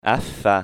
Comme la consonne dans afin
f_son.mp3